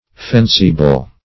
fensi-ble - definition of fensi-ble - synonyms, pronunciation, spelling from Free Dictionary Search Result for " fensi-ble" : The Collaborative International Dictionary of English v.0.48: Fensi-ble \Fen"si-ble\, a. Fencible.